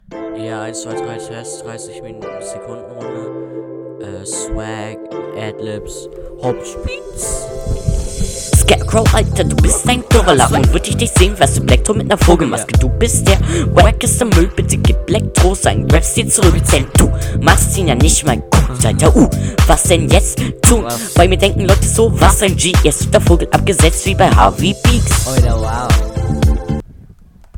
Driveby Runde